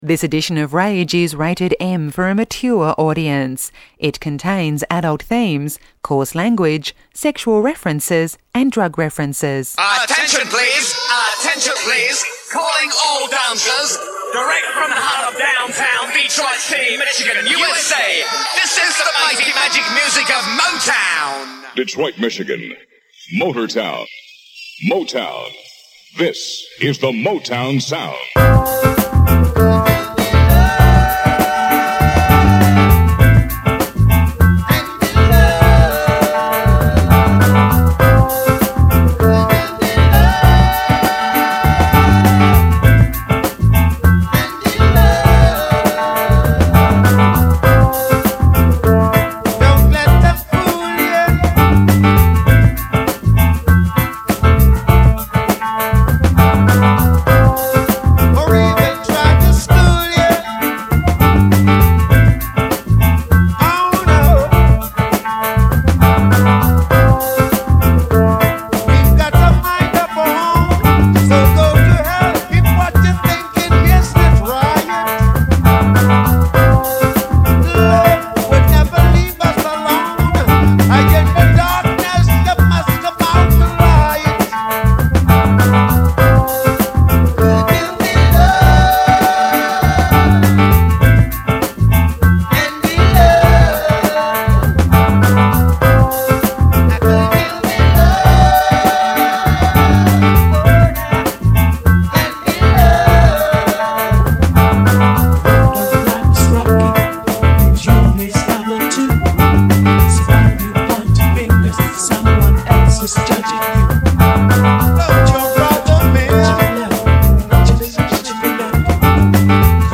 My mashup
mashup mix remix